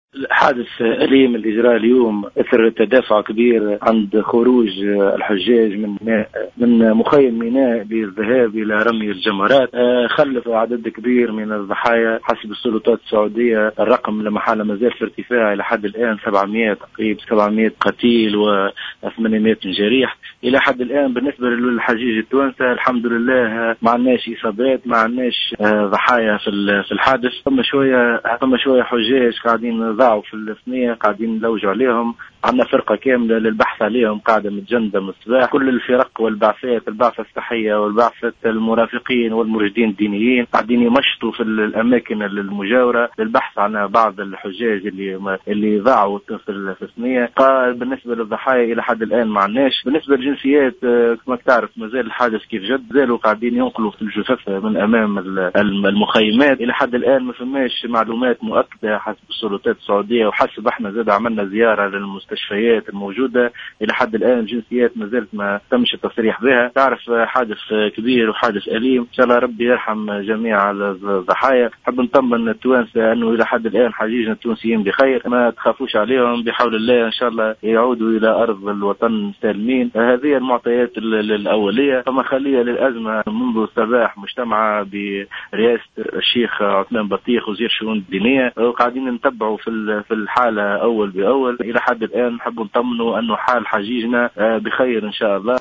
أكد المستشار لدى رئيس الجمهورية ونائب رئيس الوفد الرسمي للحجيج التونسيين فيصل الحفيان في تصريح للجوهرة "اف ام" سلامة الحجيج التونسيين من الحادث الذي جد اليوم والذي خلف أكثر من 700 قتيلا و800 جريحا على الأقل .